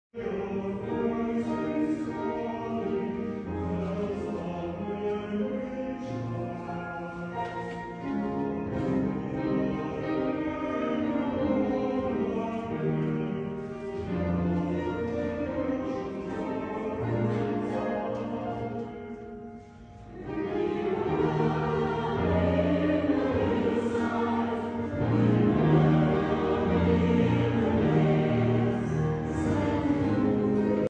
Emporia’s Saint David’s Day concert took place in the Emporia Presbyterian Church and featured a wide selection of music and literature, ranging from a harp solo to a choir.
st-davids-choir.mp3